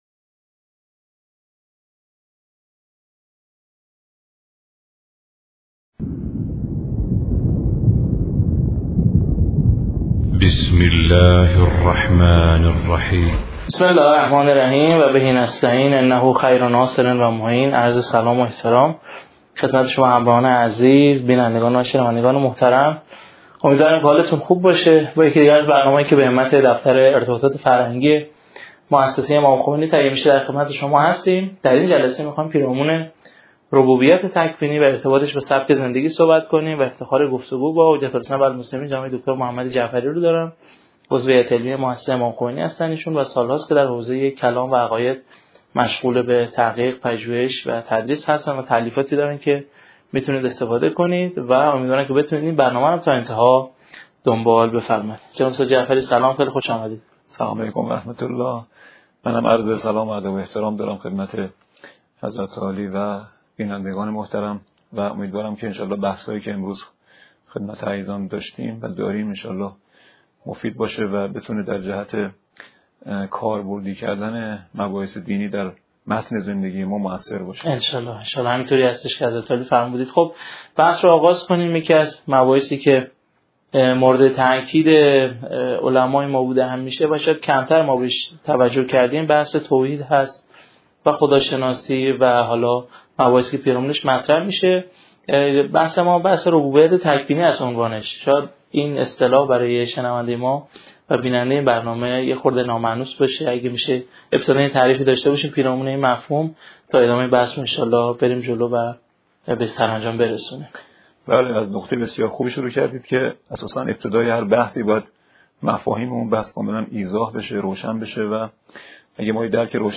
صوت فلسفه سیاست 📖 درس اول